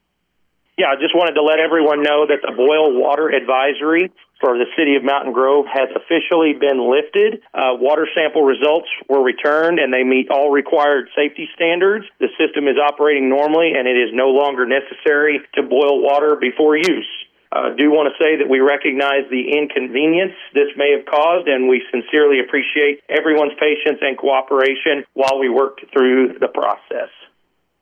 This announcement comes from the City Administrator, Dakota Bates: